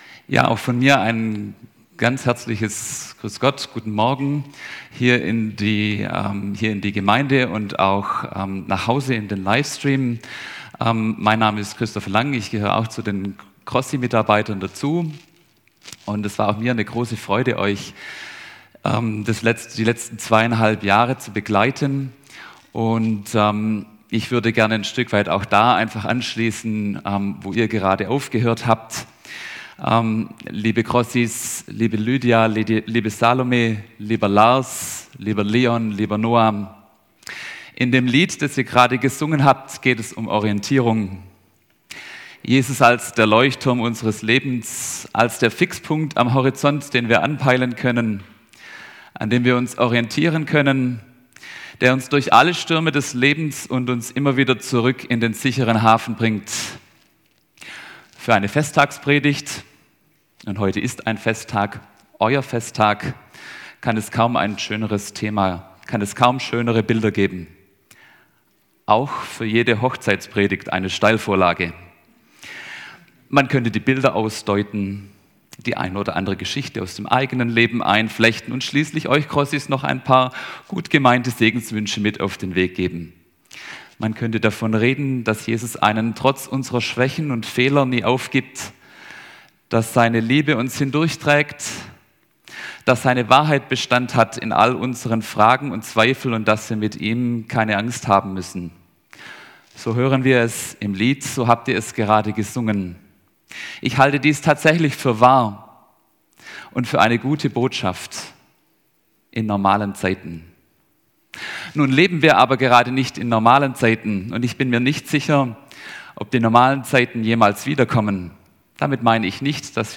Crossi-Abschluss-Gottesdienst